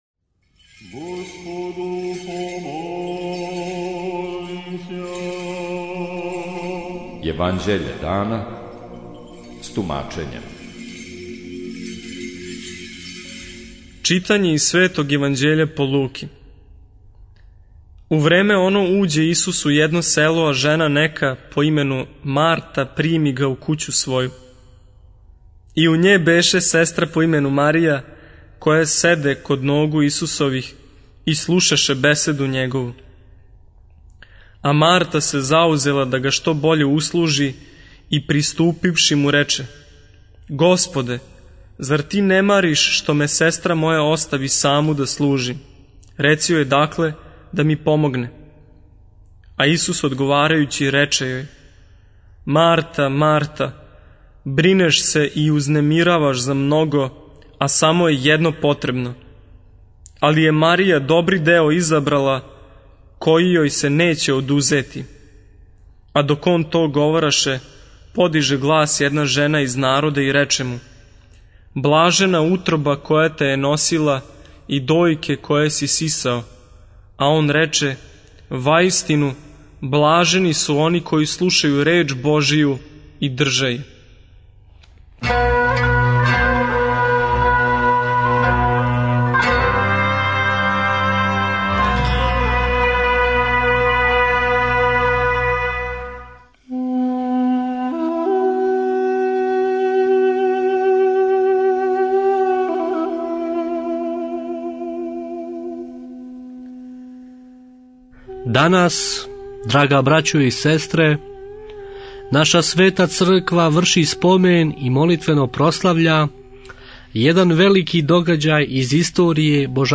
Читање Светог Јеванђеља по Матеју за дан 12.02.2026. Зачало 11.